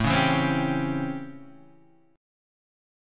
A7#9 chord {5 4 2 5 2 3} chord